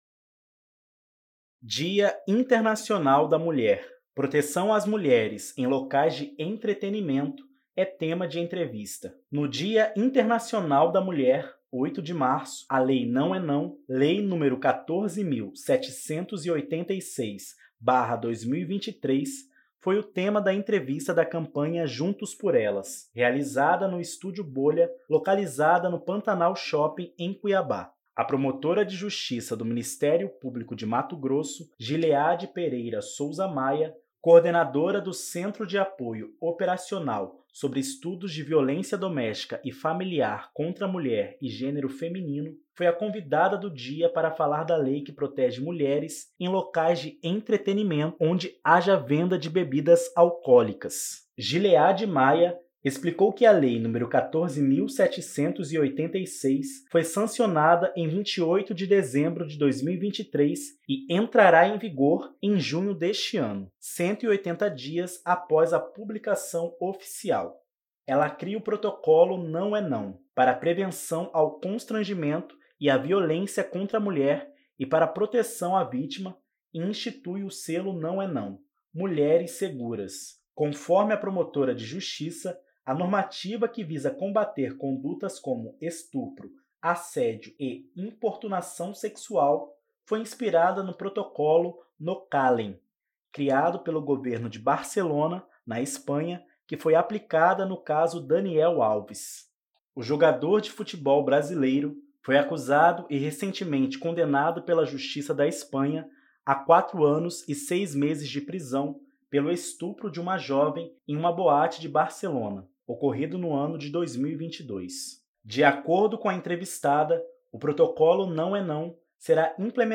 Proteção às mulheres em locais de entretenimento é tema de entrevista.mp3